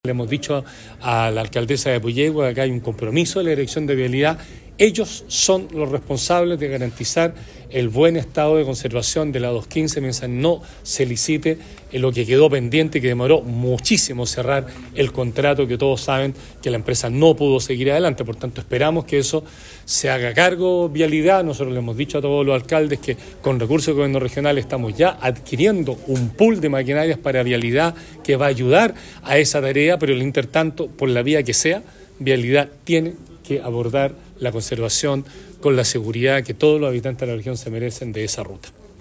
Por su parte, el Gobernador Regional Patricio Vallespin, indicó que se está adquiriendo un pool de maquinaria para la Dirección Regional de Vialidad, para tratar de solucionar temporalmente la situación de la Ruta Internacional 215 y dar seguridad a los vecinos de la Provincia de Osorno.
26-septiembre-23-patricio-vallespin-vialidad.mp3